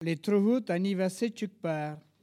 Collectif atelier de patois
Catégorie Locution